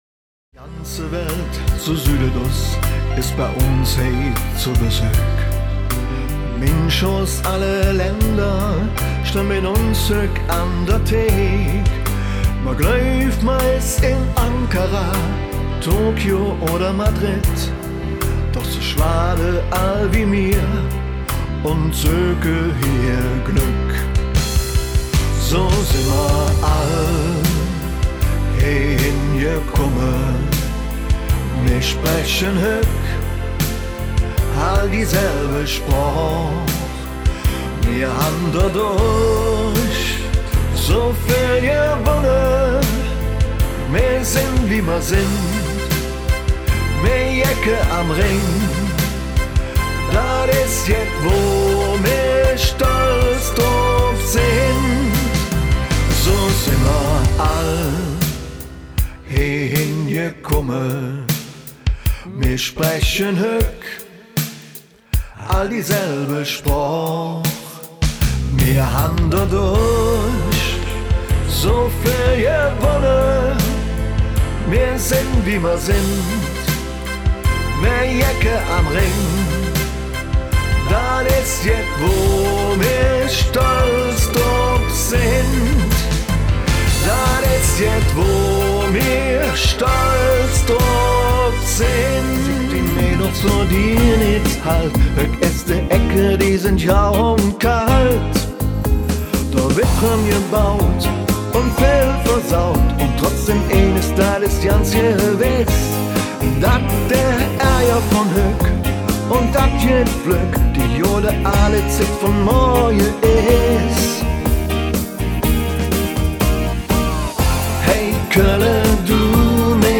kölsche Lieder